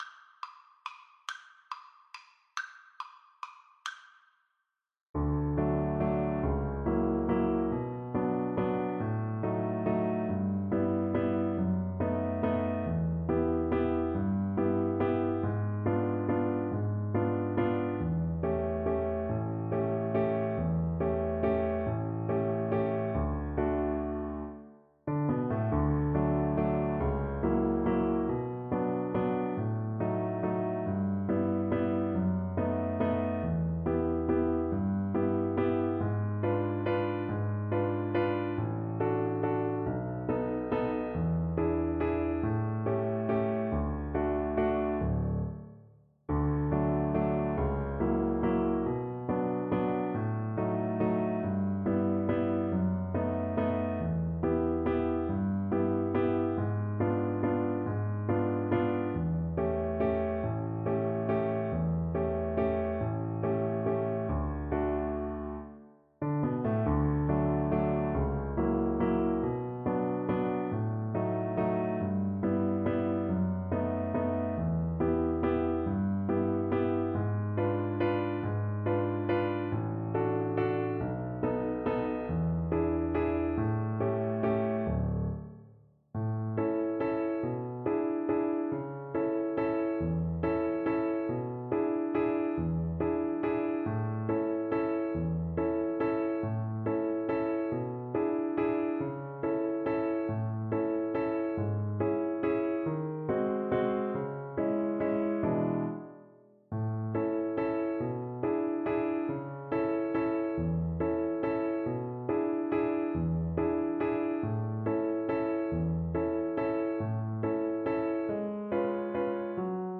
Cello
D major (Sounding Pitch) (View more D major Music for Cello )
[Waltz, one in a bar] = 140
3/4 (View more 3/4 Music)
Classical (View more Classical Cello Music)
Brazilian